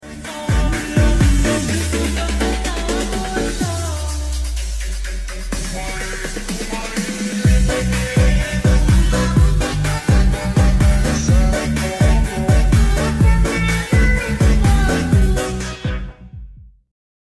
misuh tp soft spoken😭
misuh soft spoken mas 😭😭